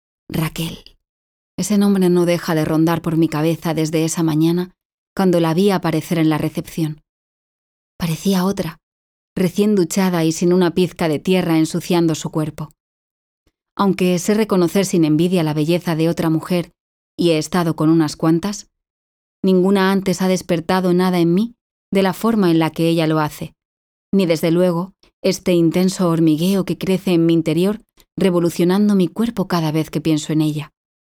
Audiolibro Acceso prohibido (Forbidden Access)